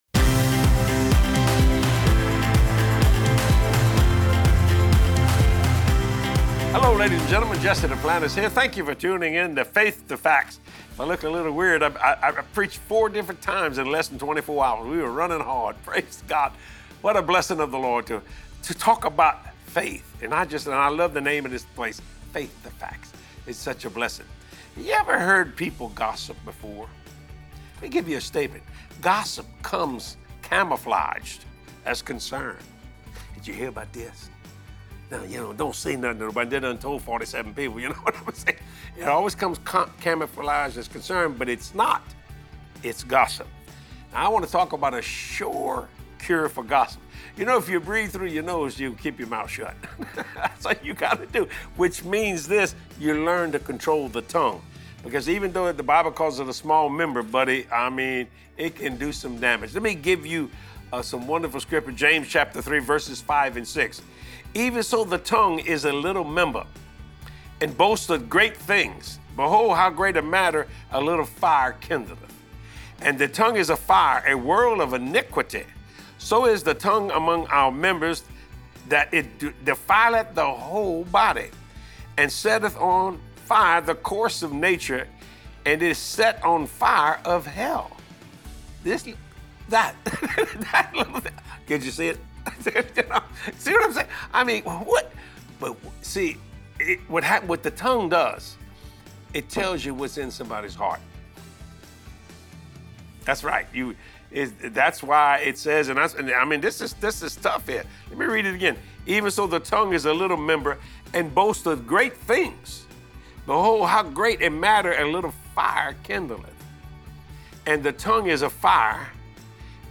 Are you having a tough time controlling your tongue? Watch this great teaching from Jesse and be compelled to PRAY instead of gossip!